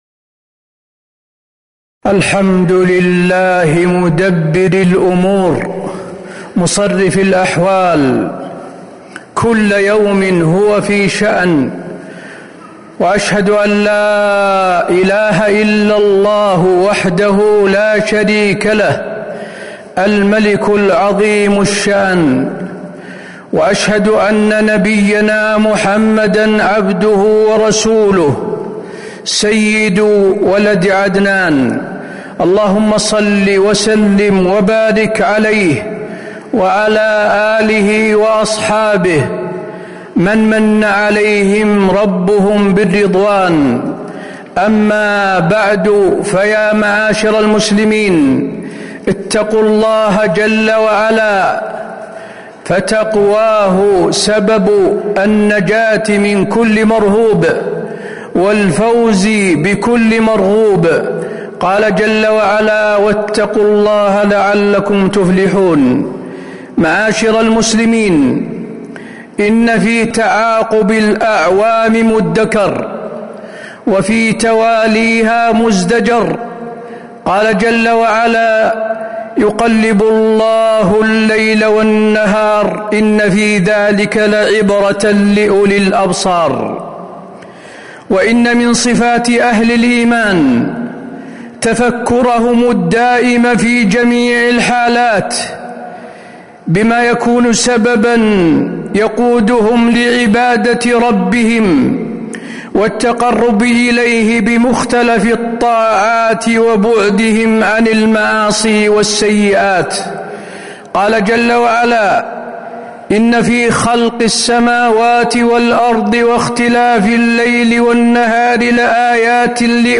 تاريخ النشر ٢٥ ذو الحجة ١٤٤٦ هـ المكان: المسجد النبوي الشيخ: فضيلة الشيخ د. حسين بن عبدالعزيز آل الشيخ فضيلة الشيخ د. حسين بن عبدالعزيز آل الشيخ فضل اغتنام الأوقات